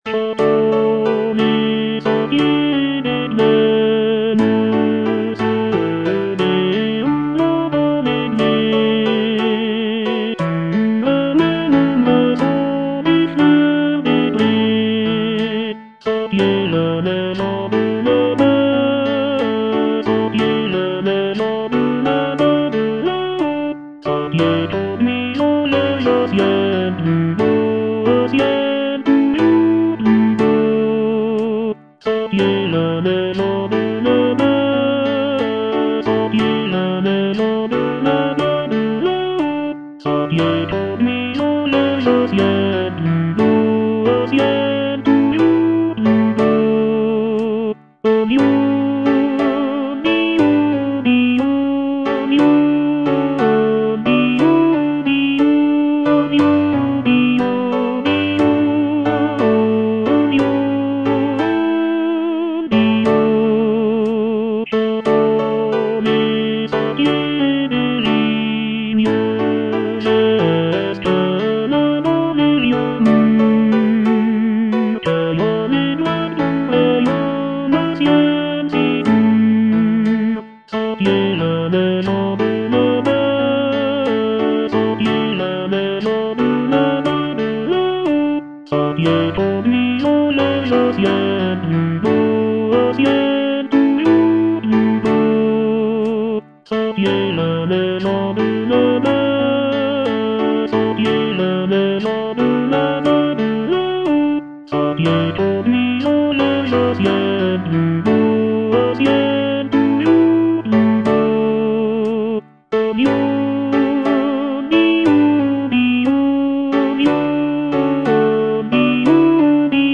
G. HAENNI - LES SENTIERS VALAISANS Tenor (Voice with metronome) Ads stop: auto-stop